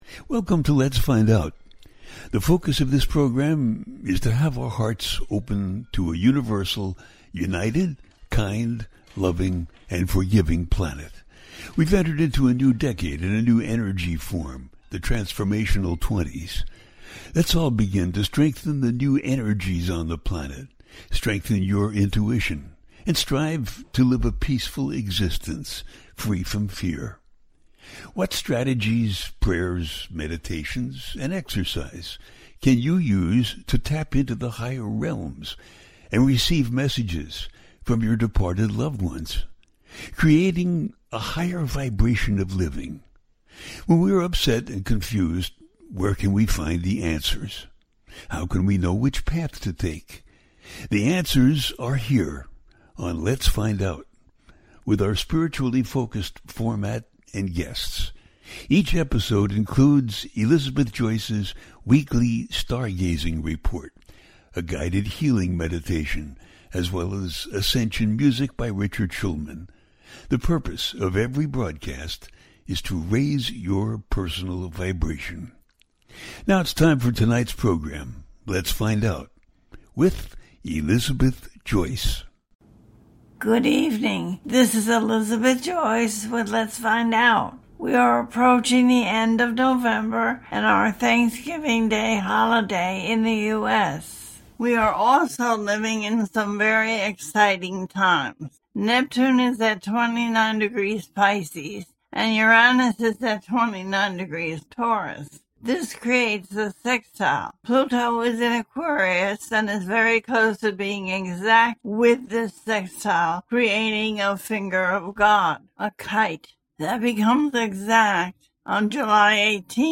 The New Moon In Scorpio The 3i Atlas - A teaching show
The listener can call in to ask a question on the air.
Each show ends with a guided meditation.